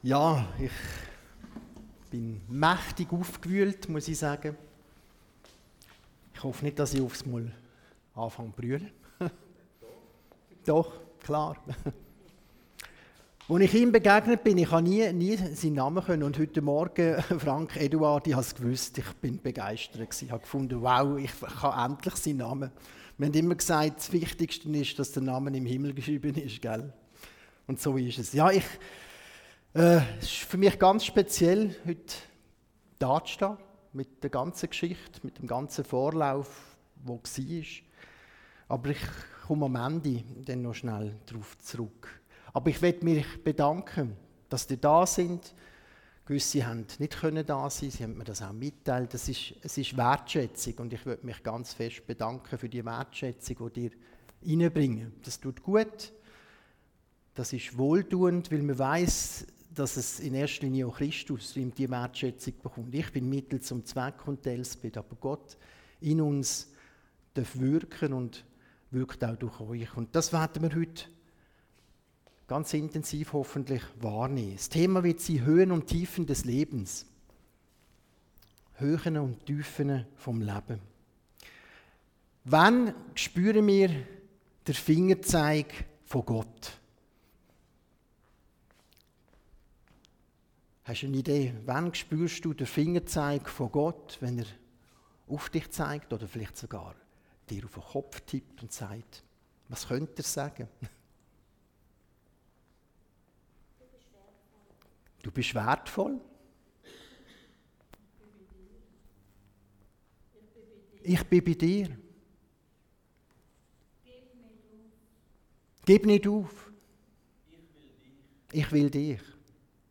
Von Serien: "Allgemeine Predigten"